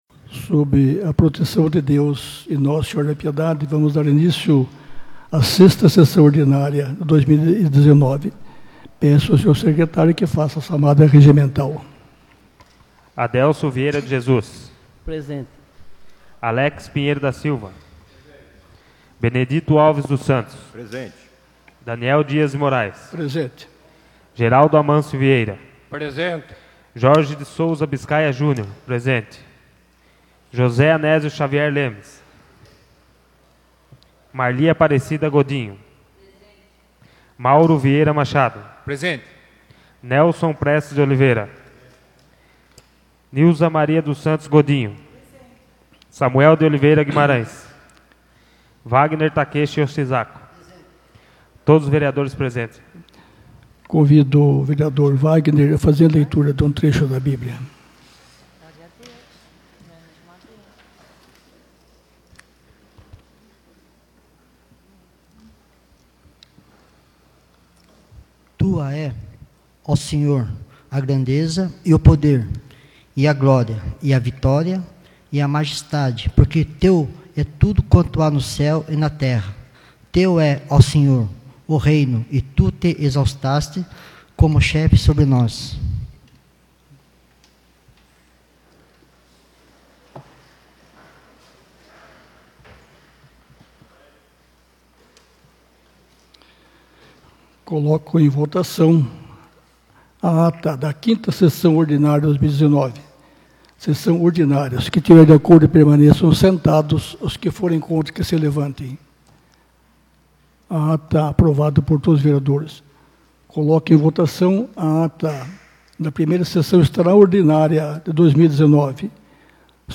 6ª Sessão Ordinária de 2019 — Câmara Municipal de Piedade